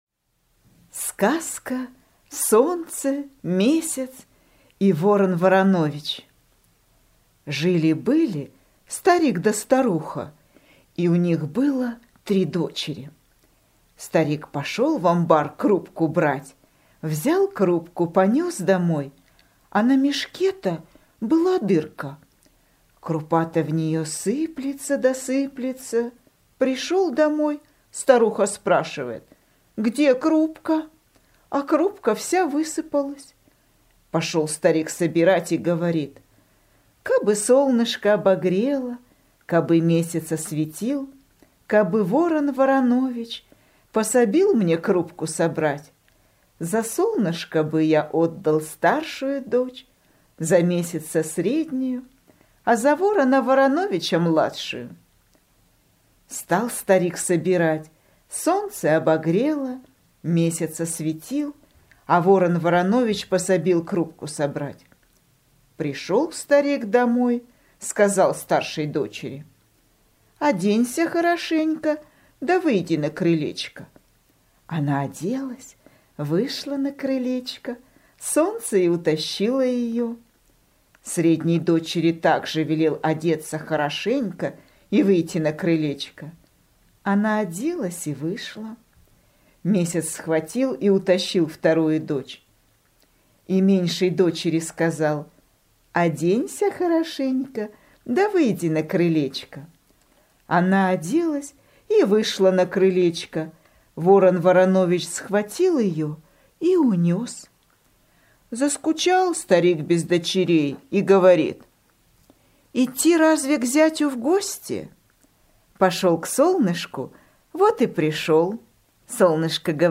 Слушать Солнце, Месяц и Ворон Воронович - русская народная аудиосказка.